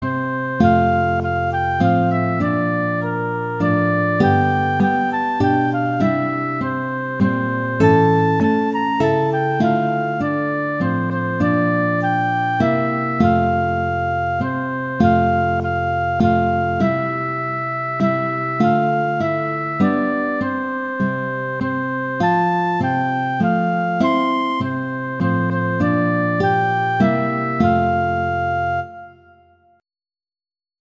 Instrumentation: flute & guitar
arrangements for flute and guitar